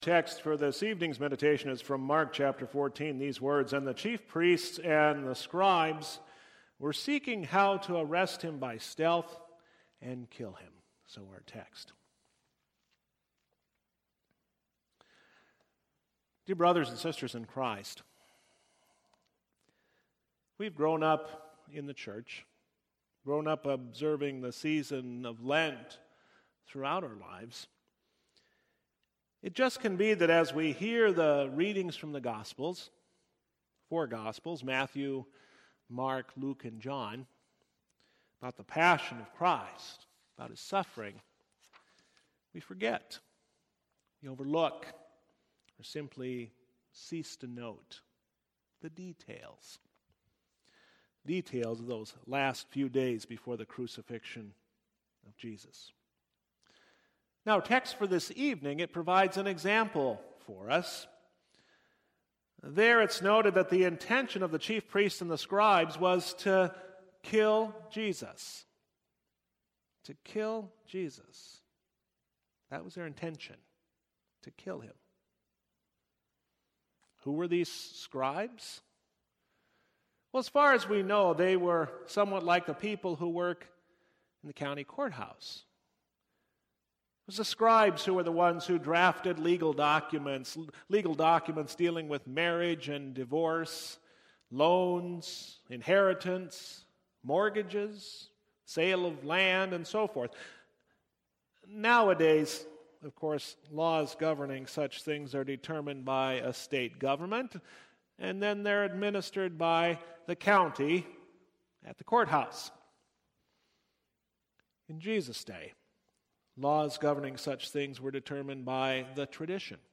Series: Lenten Midweek